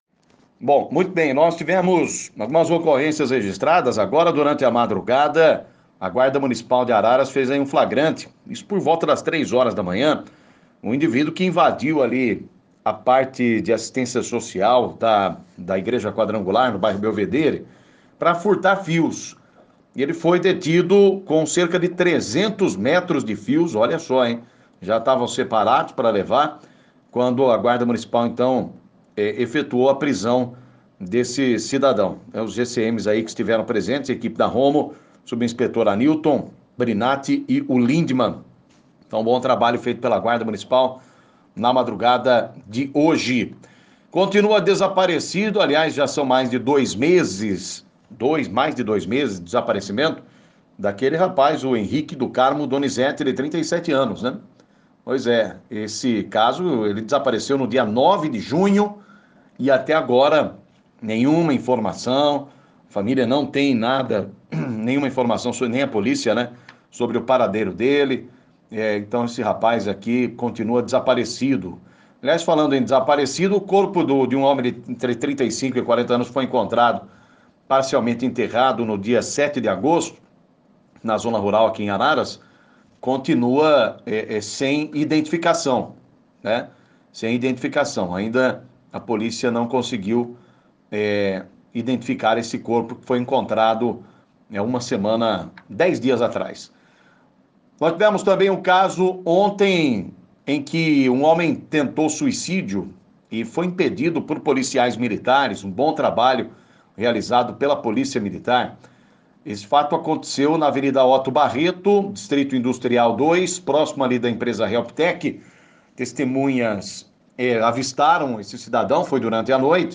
Clique no link abaixo e ouça as principais notícias de Araras e região na voz do repórter policial